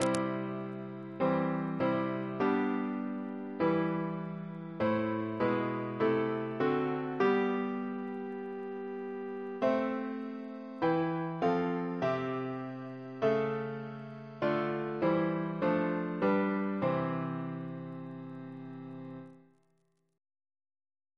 Double chant in C Composer: Martin Luther (1483-1546) Reference psalters: ACP: 99; PP/SNCB: 97